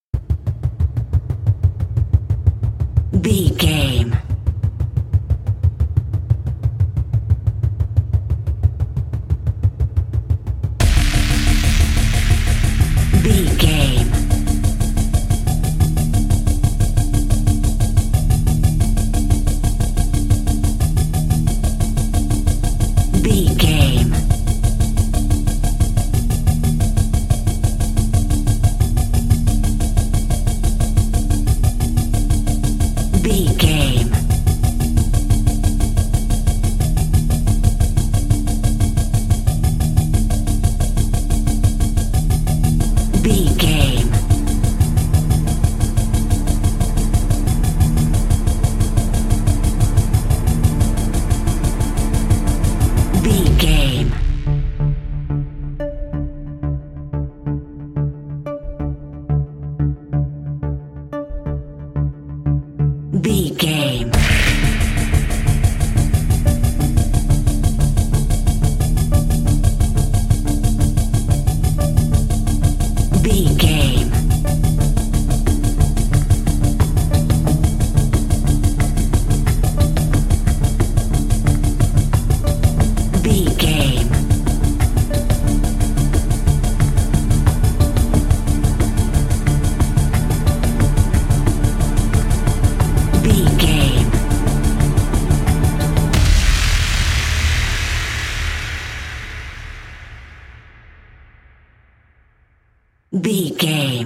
Thriller
Aeolian/Minor
drum machine
synthesiser
electric piano
percussion
ominous
dark
suspense
haunting
creepy